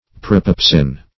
Propepsin \Pro*pep"sin\, n. [Pref. pro- + pepsin.]